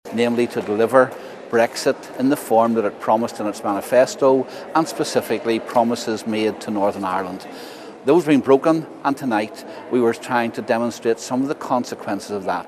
Last night, the DUP’s Sammy Wilson said that deal isn’t dead – but insists ministers have to stick to their side of the bargain: